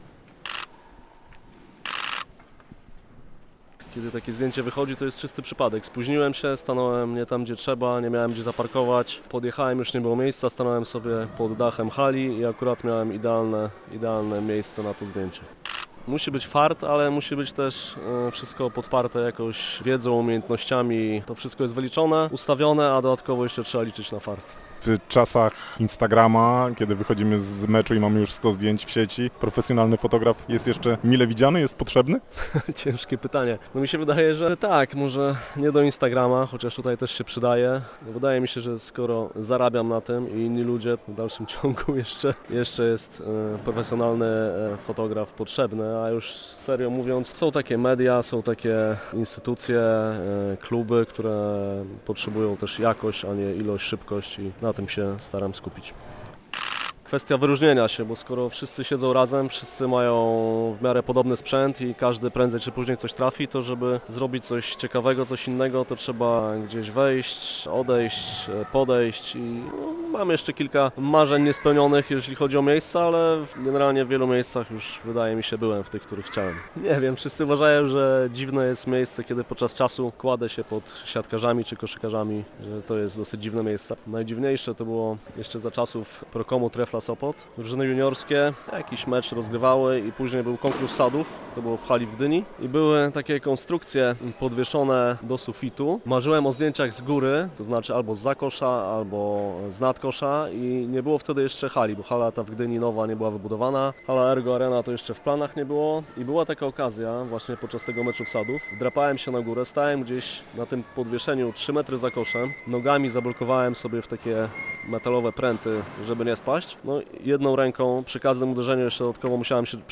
rozmawiamy w sopockiej Hali 100-lecia.